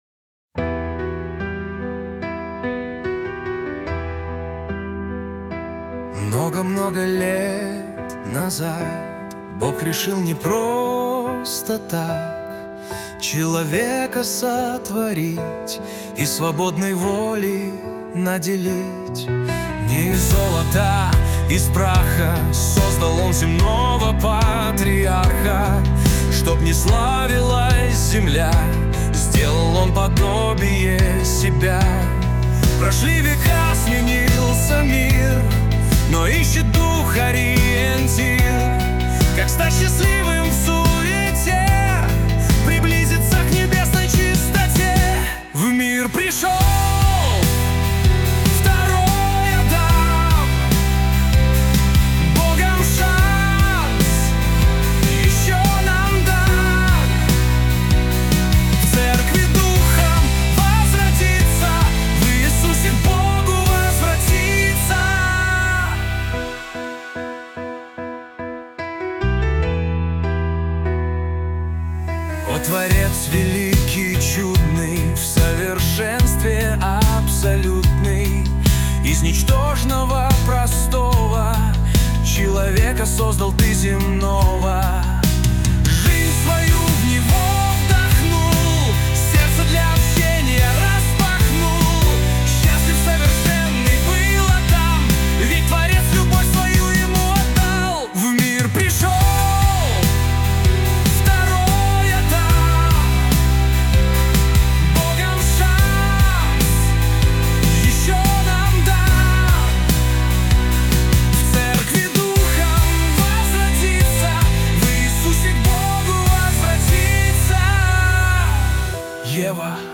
песня ai
472 просмотра 1277 прослушиваний 93 скачивания BPM: 73